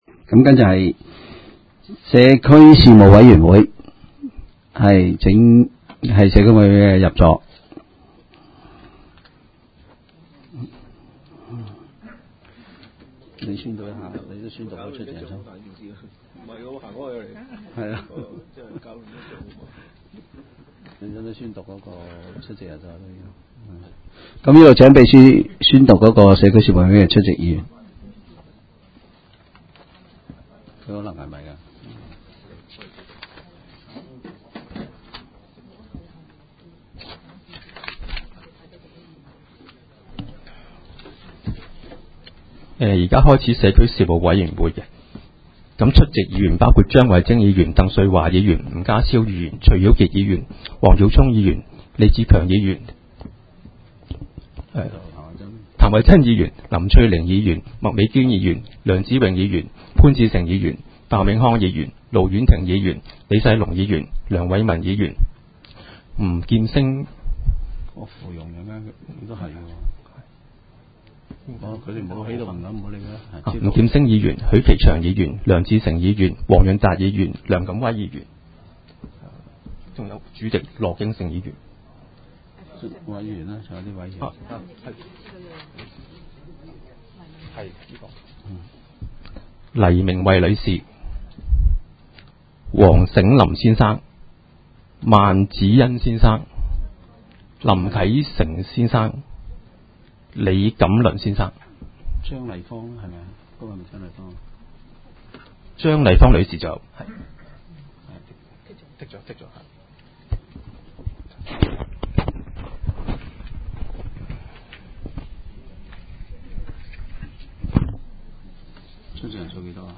委员会会议的录音记录
社区事务委员会第一次特别会议会议 日期: 2018-01-03 (星期三) 时间: 下午3时19分 地点: 香港葵涌兴芳路166-174号 葵兴政府合署10楼 葵青民政事务处会议室 议程 讨论时间 1 选举社区事务委员会主席及副主席 00:31:05 全部展开 全部收回 议程:1 选举社区事务委员会主席及副主席 讨论时间: 00:31:05 前一页 返回页首 如欲参阅以上文件所载档案较大的附件或受版权保护的附件，请向 区议会秘书处 或有关版权持有人（按情况）查询。